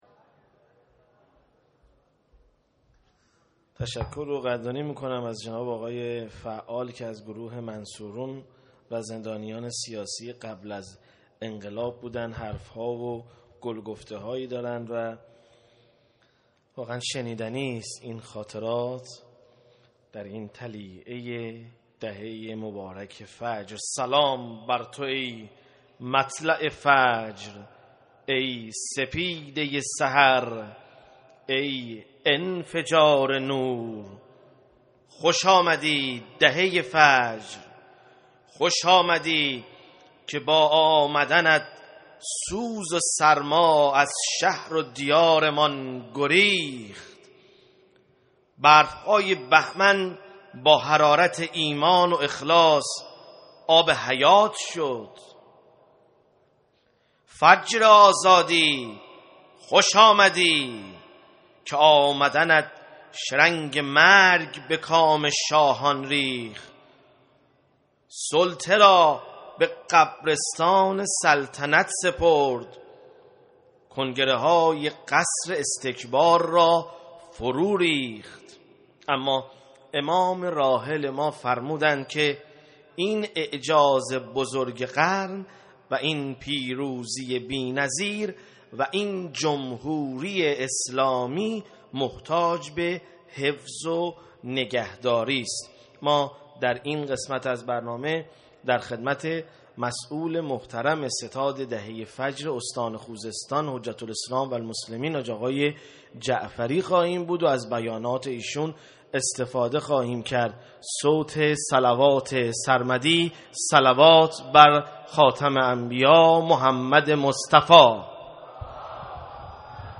سخنران